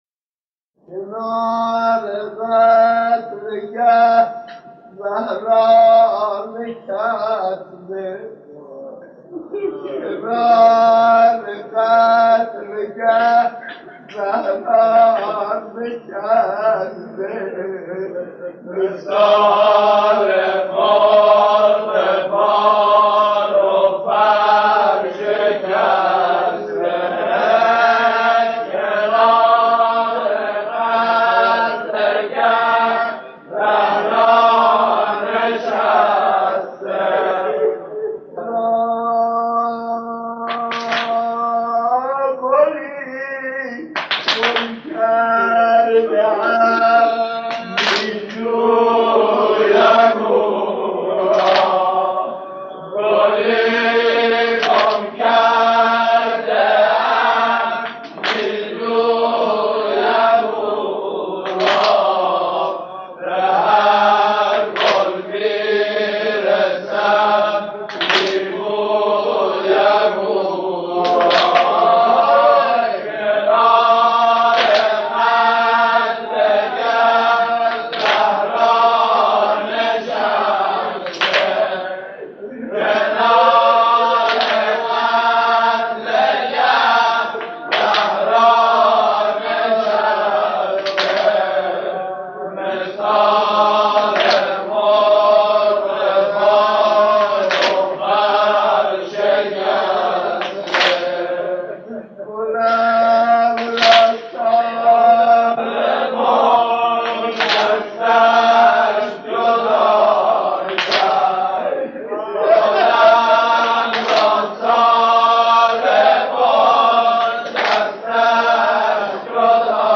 در پرده عشاق، صدای مداحان و مرثیه‌خوانان گذشته تهران قدیم را خواهید شنید که صدا و نفس‌شان شایسته ارتباط دادن مُحب و مَحبوب بوده است.
ذکر زمینه در توصیف غروب عاشورا و احوال شهیدان